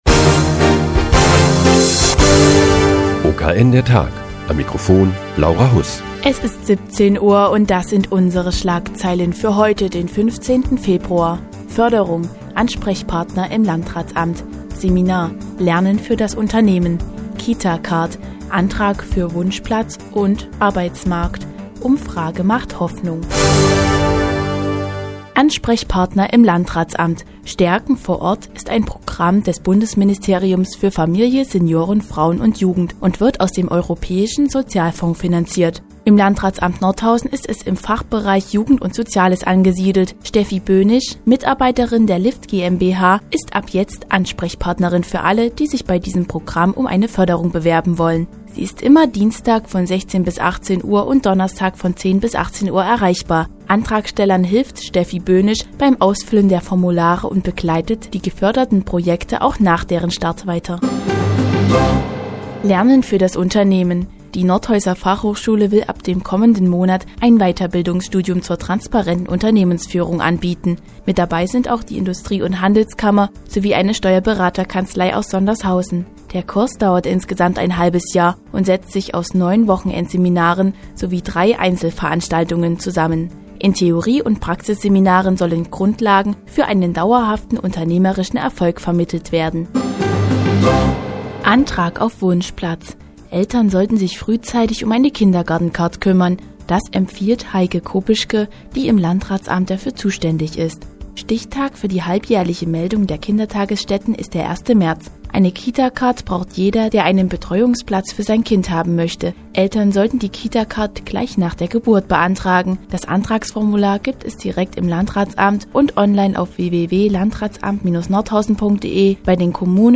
Die tägliche Nachrichtensendung des OKN ist nun auch in der nnz zu hören. Heute geht es um die Kita-Card und ein Weiterbildungsstudium der Fachhochschule Nordhausen zur transparenten Unternehmensführung.